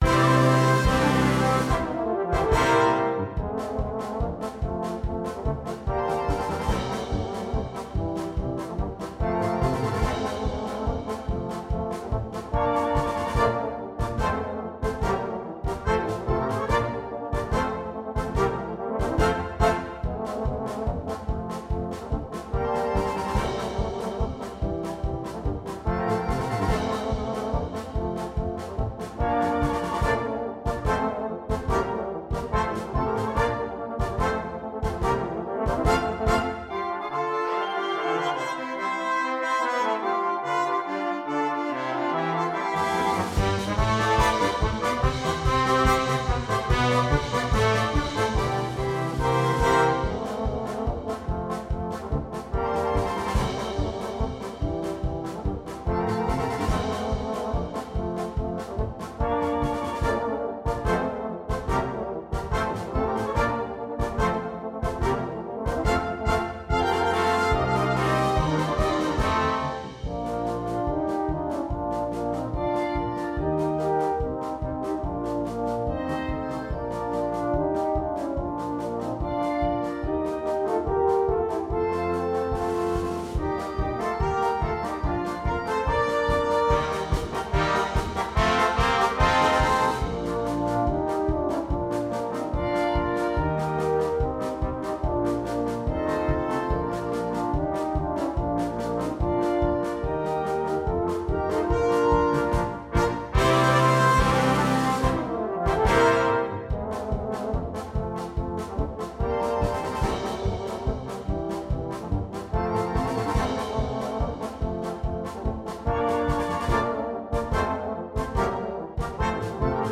2. Blaskapelle
Duett
Tenorhorn / Bariton (Duett)
Unterhaltung